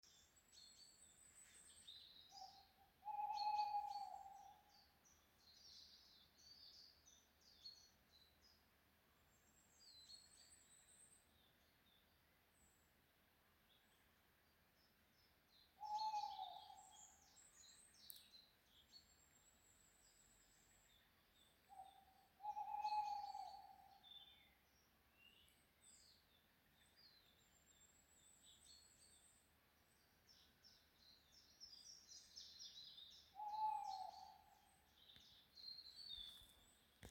Birds -> Owls ->
Tawny Owl, Strix aluco
StatusPair observed in suitable nesting habitat in breeding season